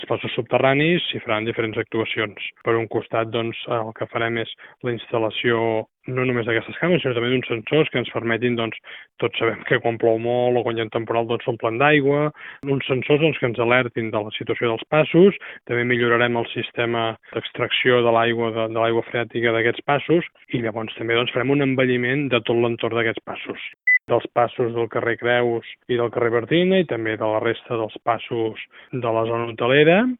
L’actuació es complementarà amb la instal·lació de càmeres de videovigilància als passos sota la via ferroviària, als quals també se’ls farà un rentat de cara, tal com ha detallat l’alcalde Buch en declaracions a Ràdio Calella TV.